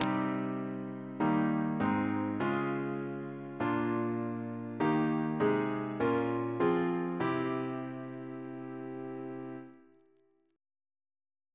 Single chant in C minor Composer: William R. Bexfield (1824-1853) Reference psalters: ACB: 361; H1940: 691; H1982: S256; PP/SNCB: 228; RSCM: 170